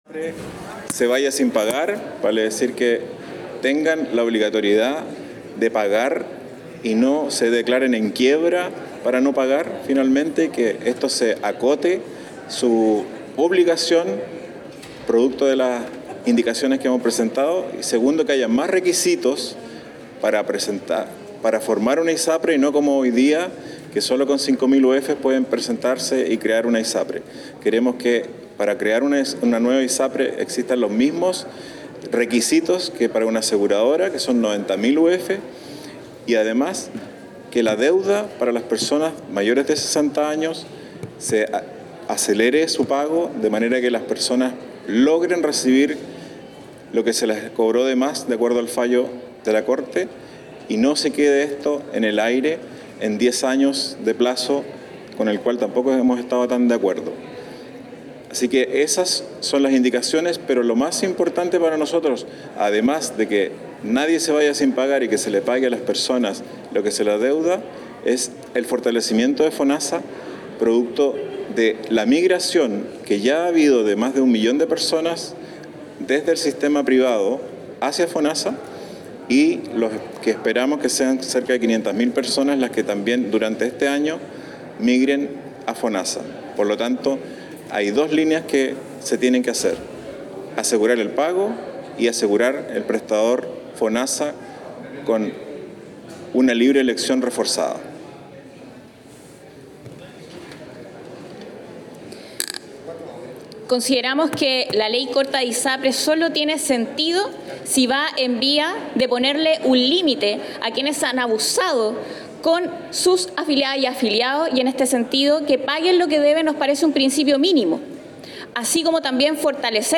CUÑA DIP ROSAS INDICACIONES
CUÑA-DIP-ROSAS-INDICACIONES.aac